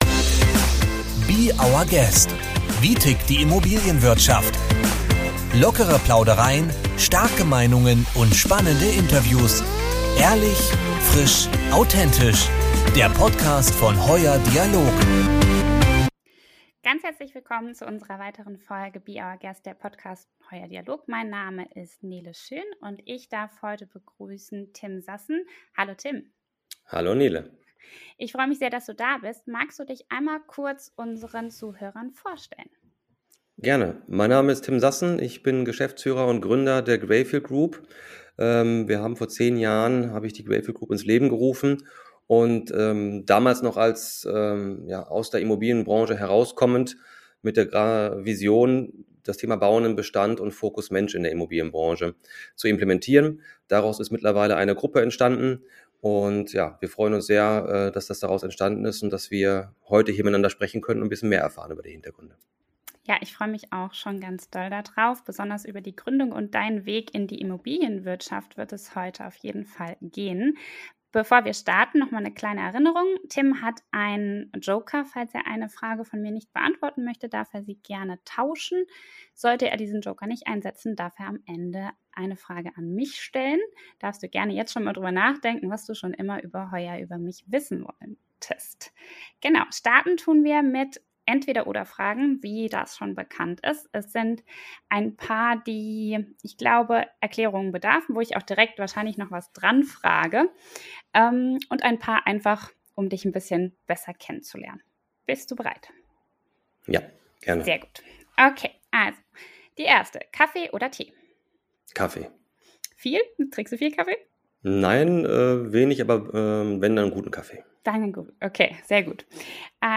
In unserem Interview nimmt er uns mit auf seinen Weg in der Immobilienbranche.